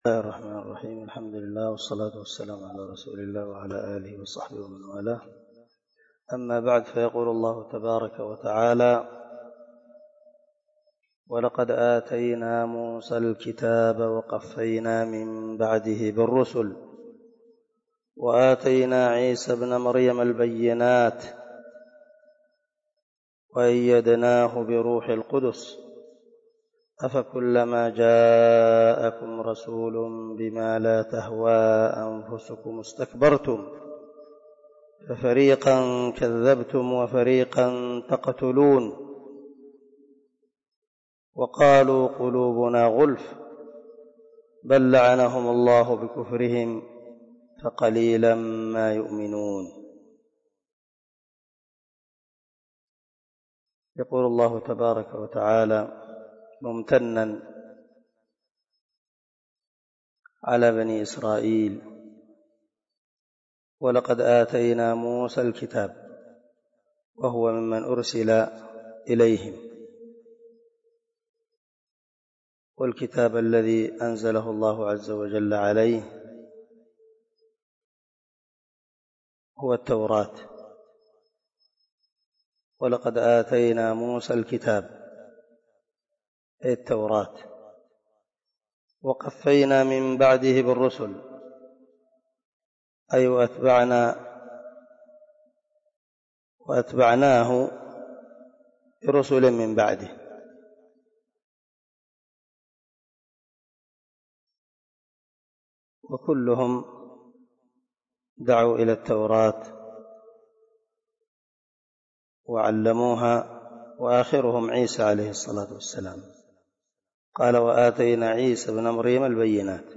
038الدرس 28 تفسير آية ( 87 - 89 ) من سورة البقرة من تفسير القران الكريم مع قراءة لتفسير السعدي